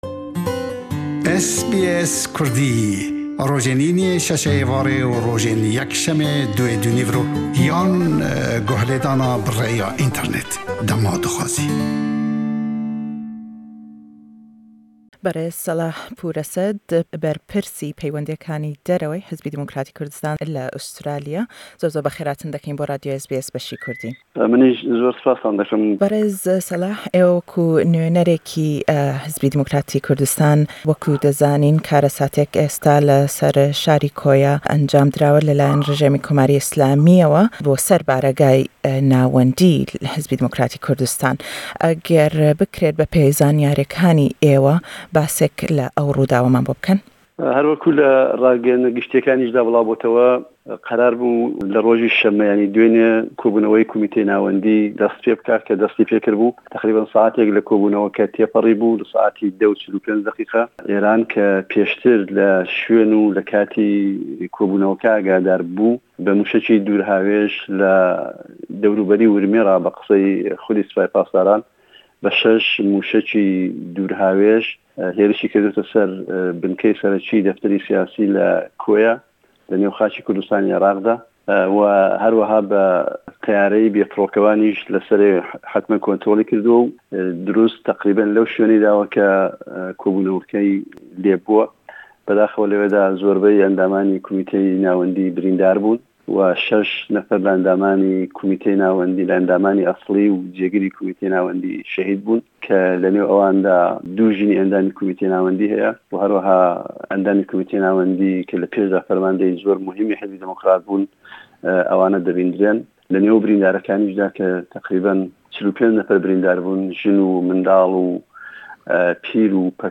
le em lêdwane da le gell SBS Kurdî dellêt ke dawadeken le willatanî zil-hêz bo ewey hellwêstîyan hebêt beramber be em hêrişaney Êran.